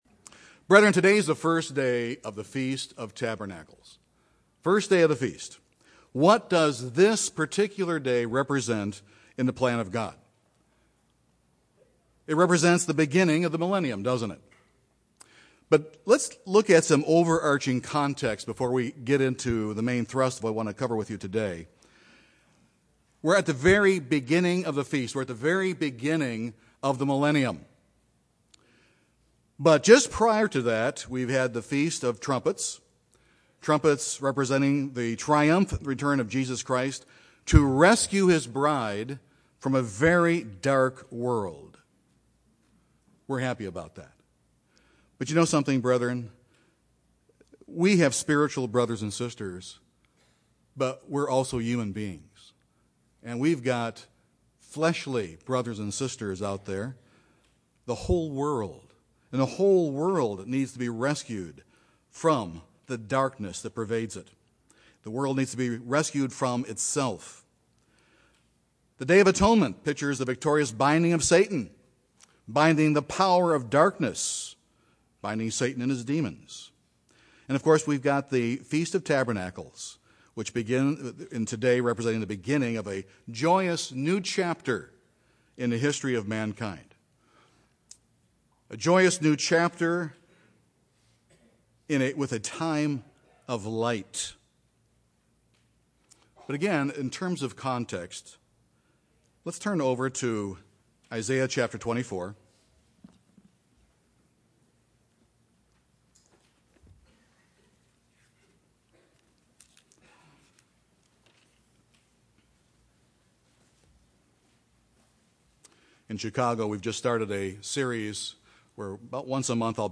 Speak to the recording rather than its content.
This sermon was given at the Wisconsin Dells, Wisconsin 2018 Feast site.